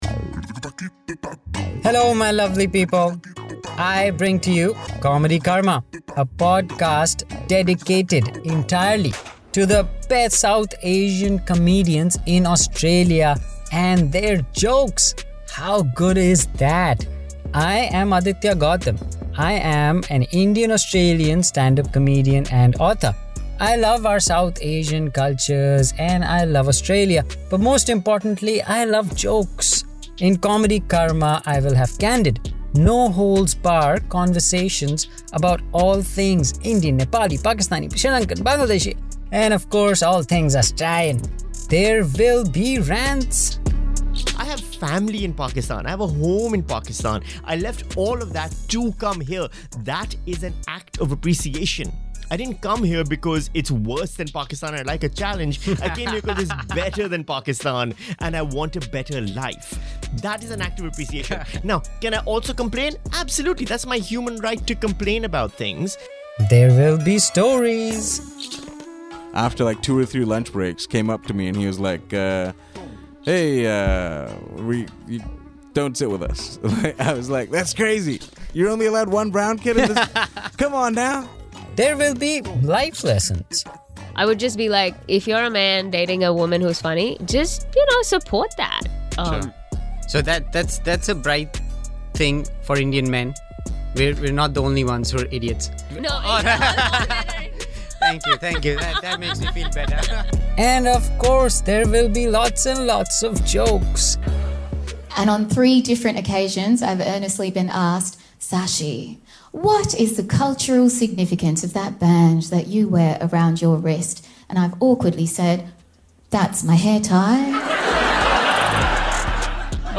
TRAILER